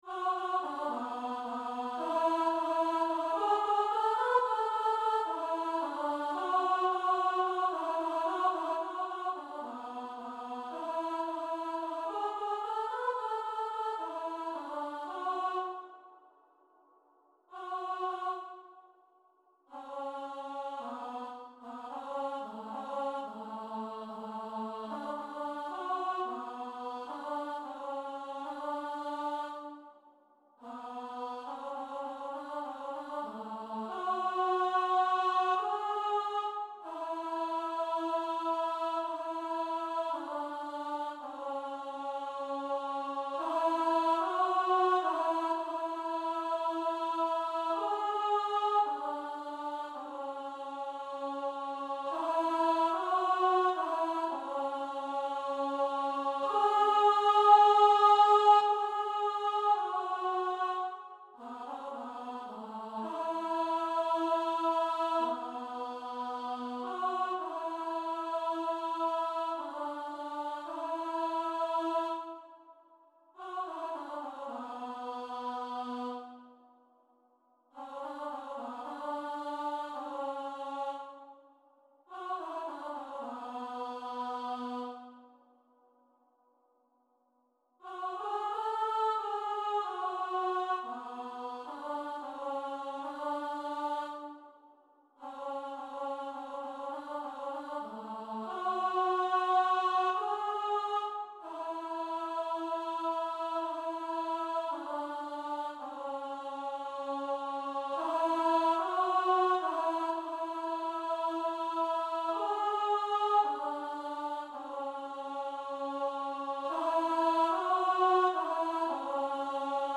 Take-On-Me-Alto.mp3